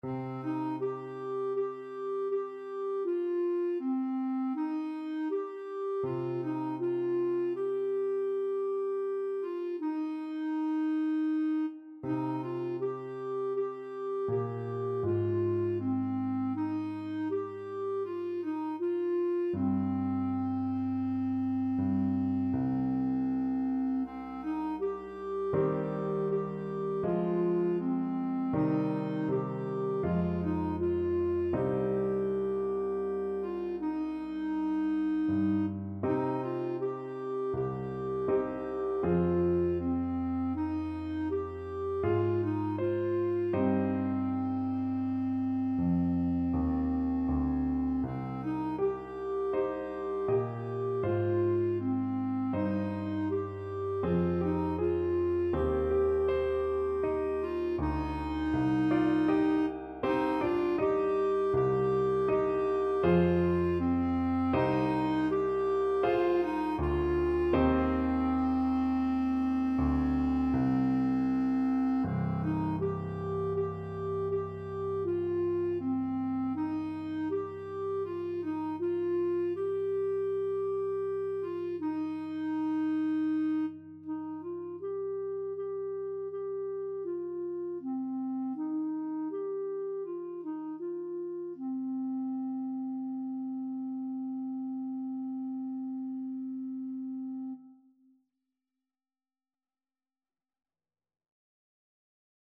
Clarinet
Traditional Music of unknown author.
Andante espressivo
C minor (Sounding Pitch) D minor (Clarinet in Bb) (View more C minor Music for Clarinet )
4/4 (View more 4/4 Music)